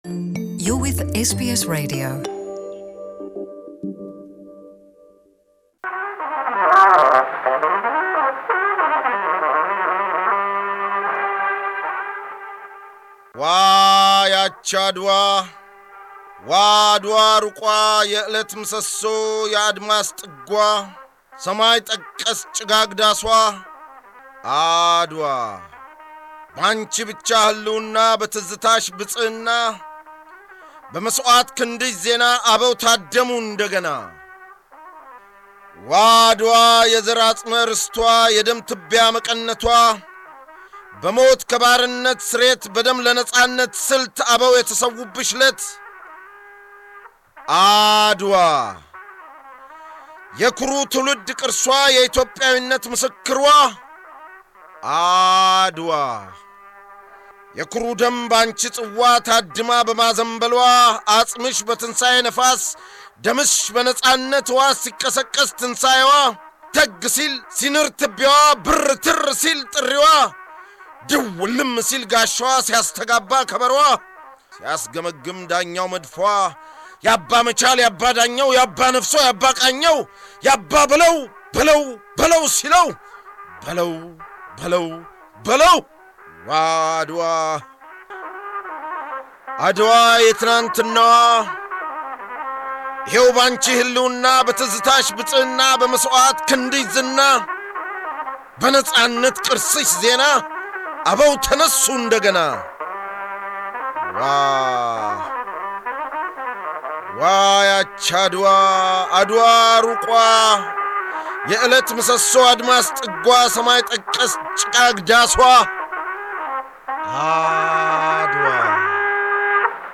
ግጥም - ብላቴን ጌታ ጸጋዬ ገብረመድኅን ድምፅ - አርቲስት ተስፋዬ ገብረሃና